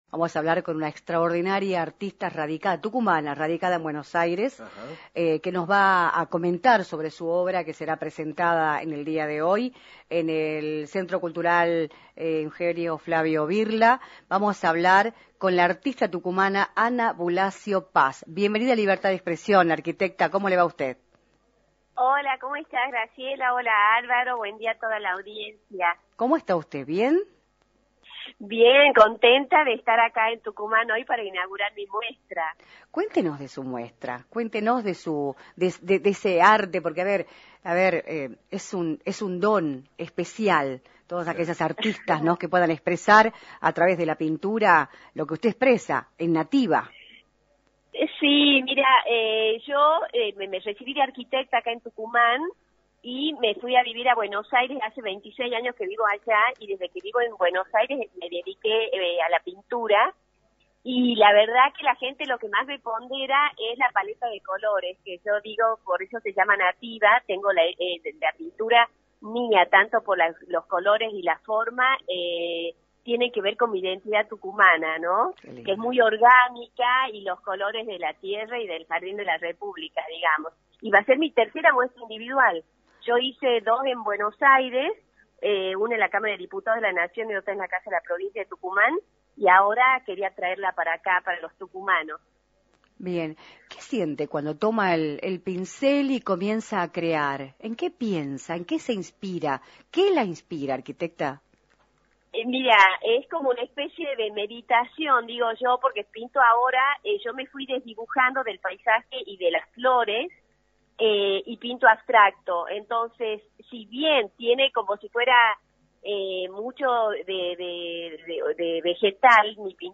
informó en «Libertad de Expresión» por la 106.9
entrevista